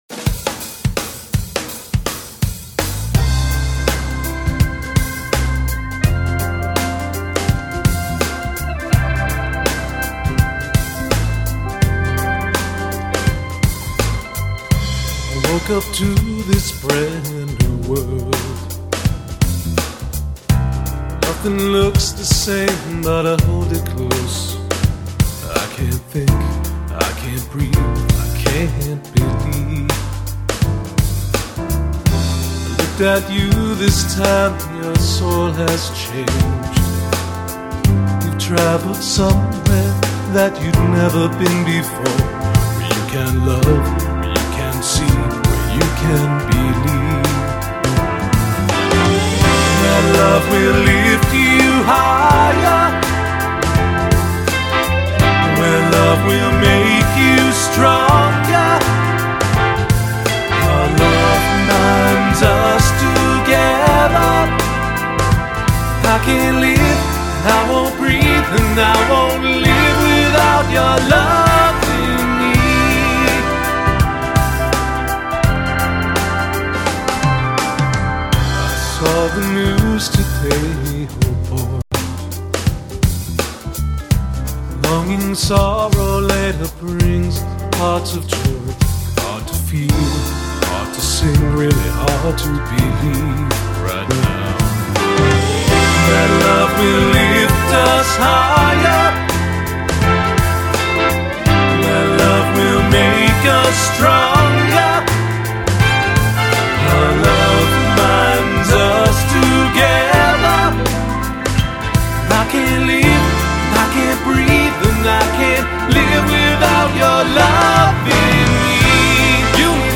"Higher." (AC/pop)